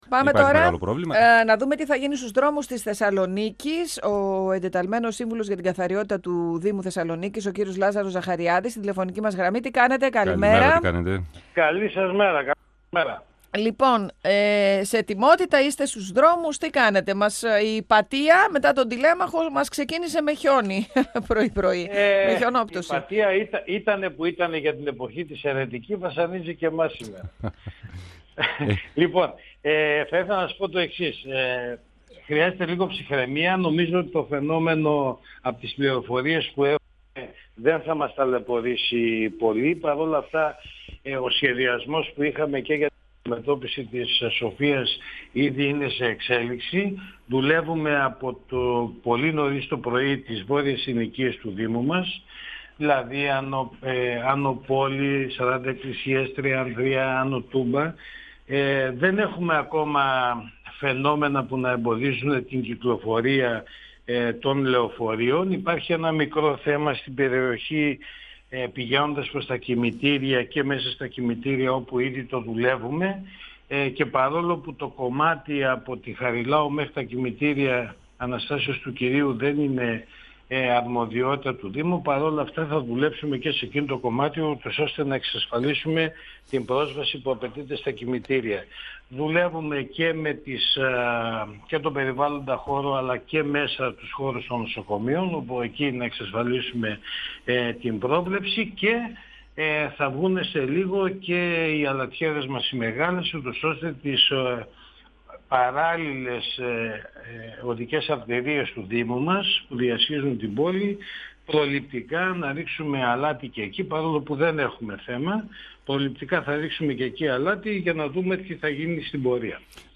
O εντεταλμένος σύμβουλος καθαριότητας του κεντρικού Δήμου, Λάζαρος Ζαχαριάδης, στον 102FM του Ρ.Σ.Μ. της ΕΡΤ3